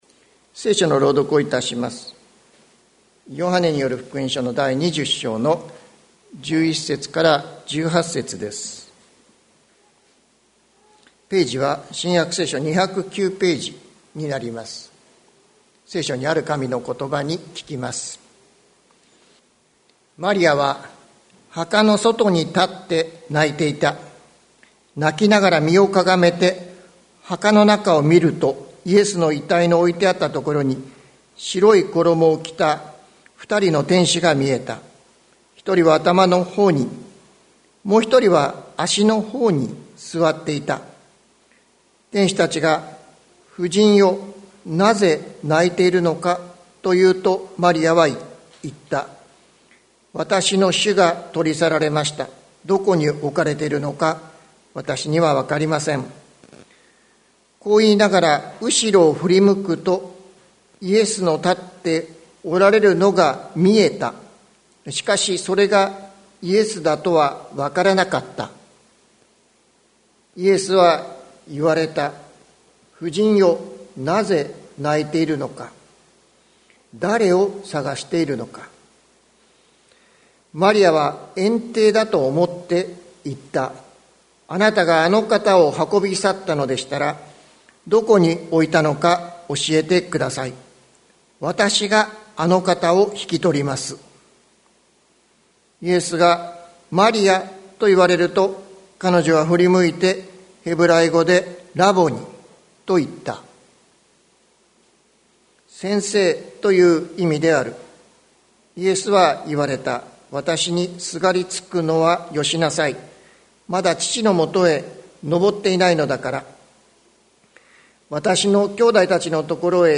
2022年04月17日朝の礼拝「振り向けば、そこに主がおられる」関キリスト教会
関キリスト教会。説教アーカイブ。